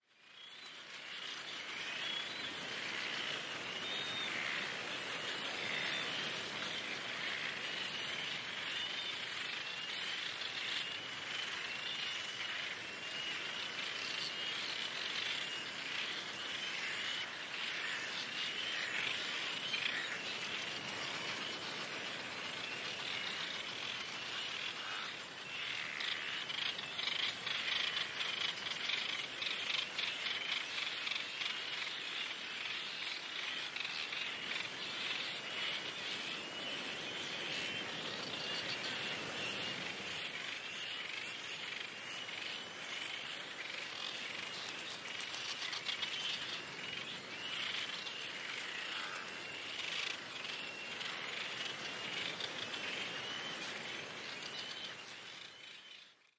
鸟类氛围
描述：塞舌尔鸟岛上的海鸟
Tag: 鸟鸣声 和平 森林 海鸟 自然 现场记录 塞舌尔